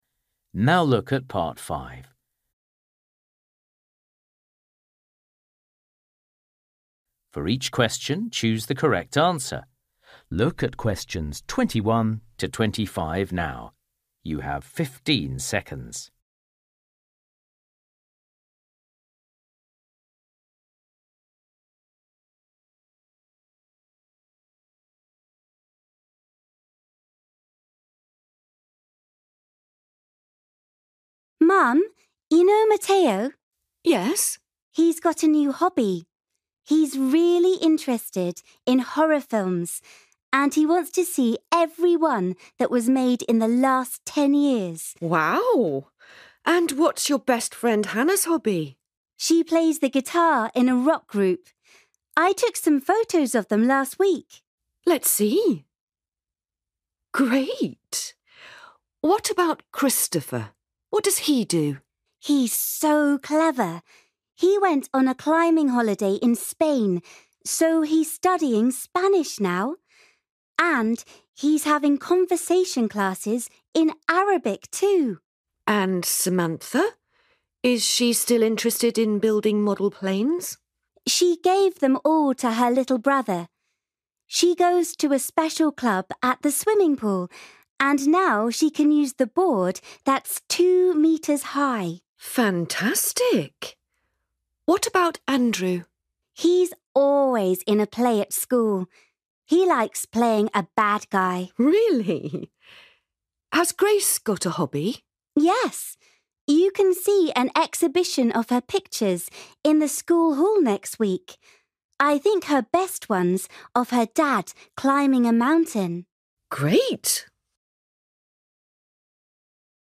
You will hear a girl talking to her mum about her friends and their hobbies.